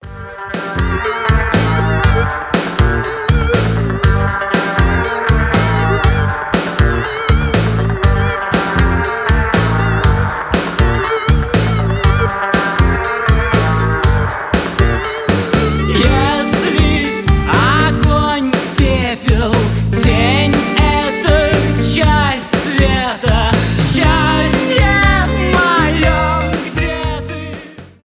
рок , indie rock , alternative rock